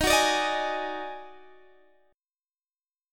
Listen to D#mM7bb5 strummed